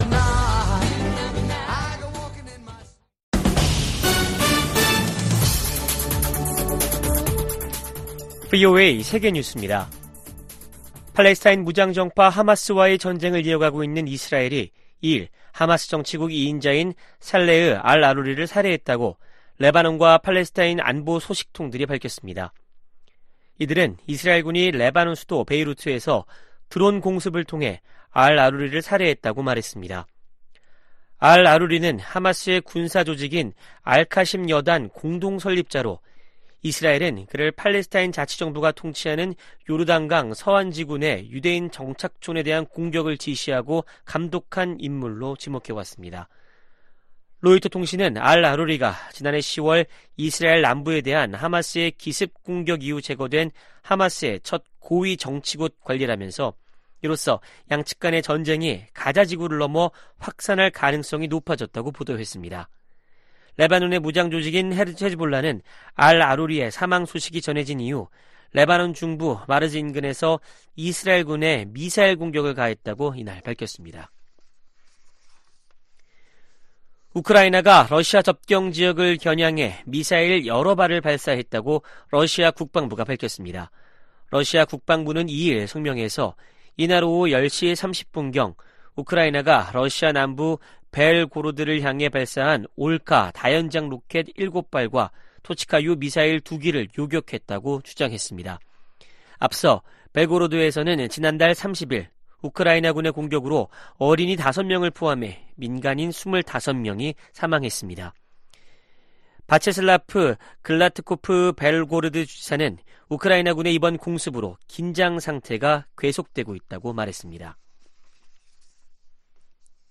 VOA 한국어 아침 뉴스 프로그램 '워싱턴 뉴스 광장' 2024년 1월 4일 방송입니다.